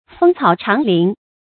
豐草長林 注音： ㄈㄥ ㄘㄠˇ ㄓㄤˇ ㄌㄧㄣˊ 讀音讀法： 意思解釋： ①茂草高林。②指隱逸之地。